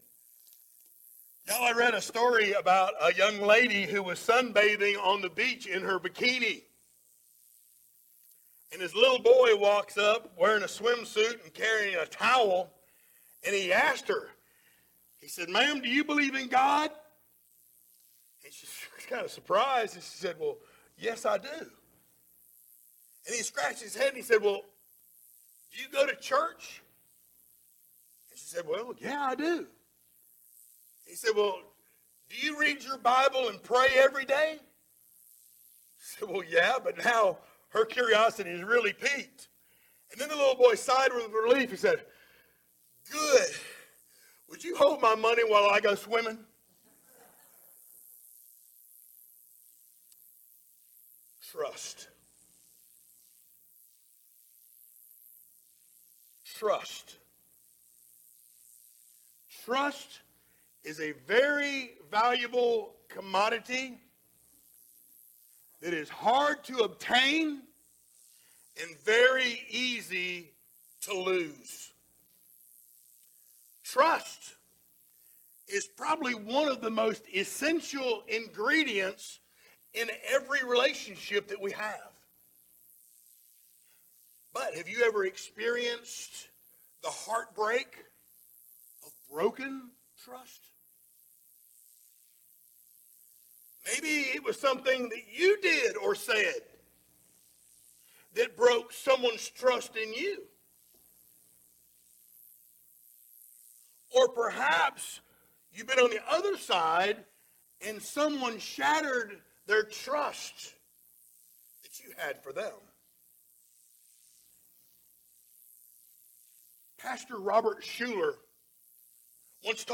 Series: sermons
44:34 Service Type: Sunday Morning Download Files Notes Topics